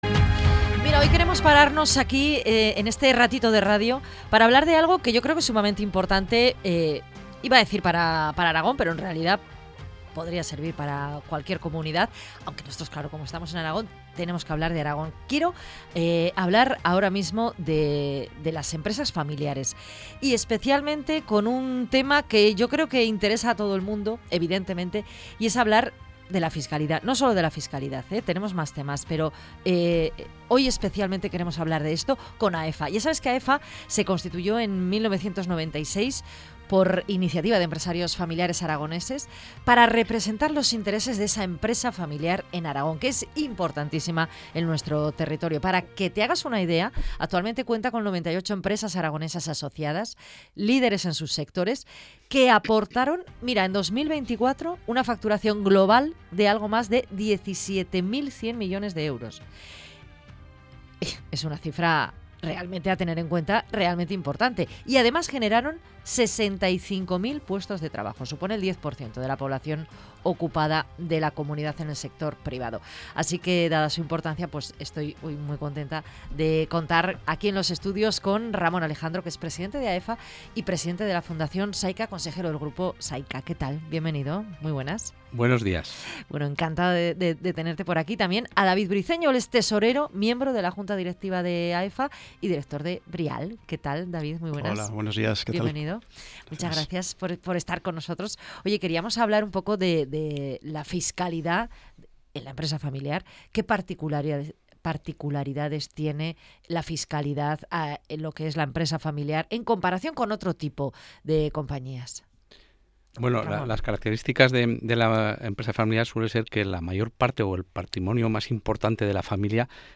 MEDIODIA COPE Tertulia